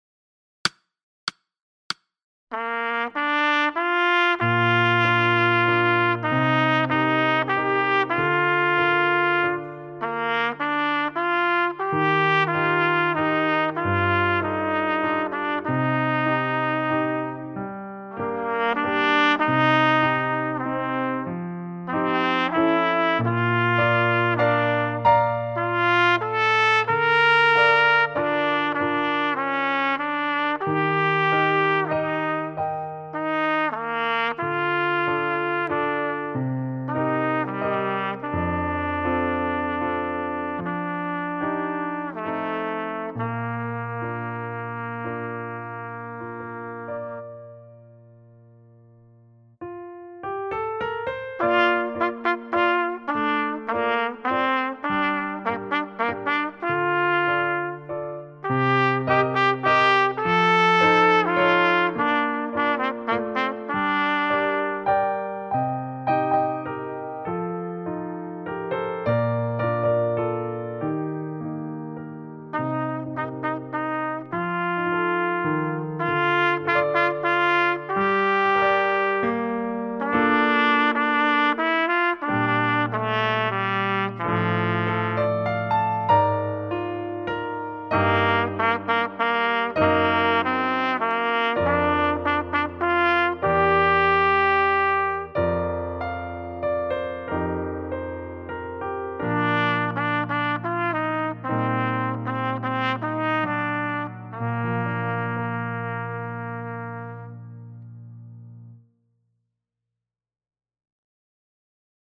Pour trompette (ou cornet) et piano